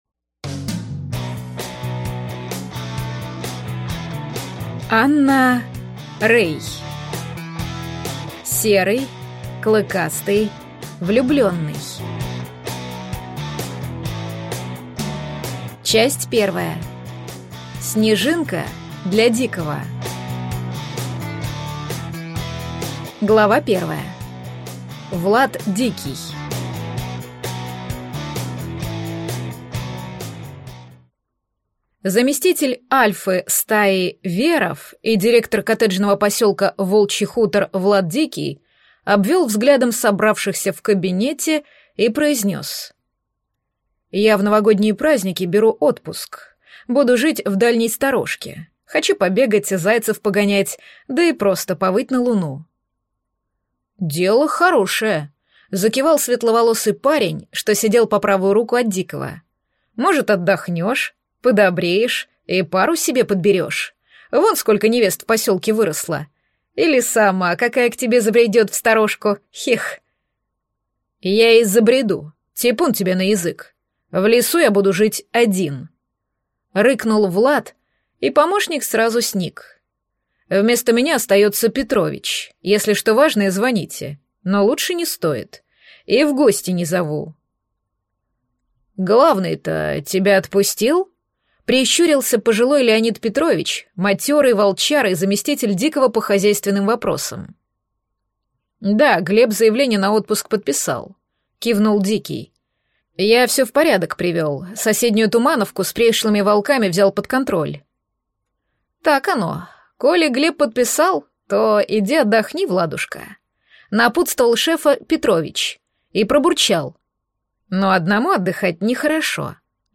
Аудиокнига Серый, клыкастый, влюбленный | Библиотека аудиокниг